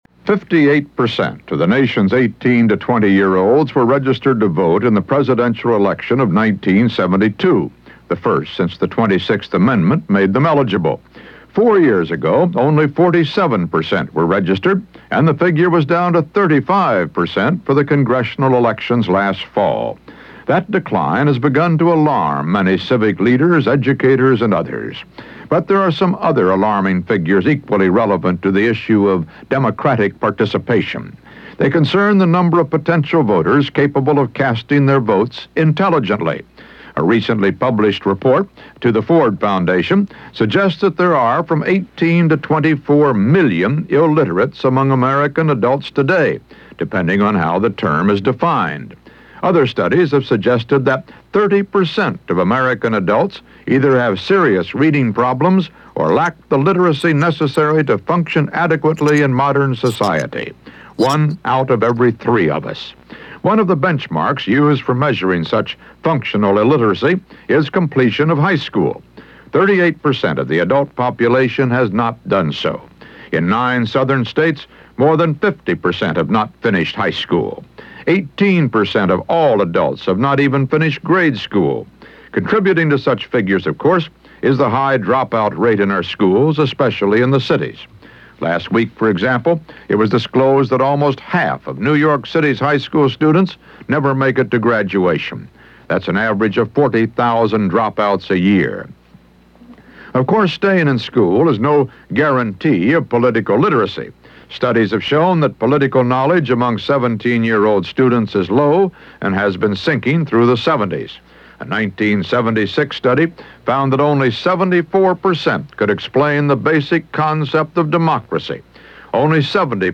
In the meantime, here is a commentary from Walter Cronkite, part of his News and Analysis for CBS Radio on October 24, 1979.